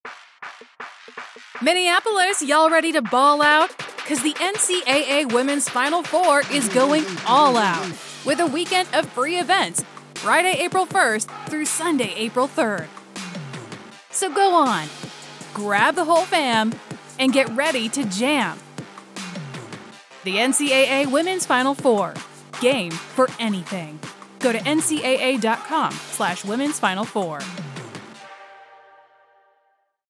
Female
English (North American)
Yng Adult (18-29), Adult (30-50)
Television Spots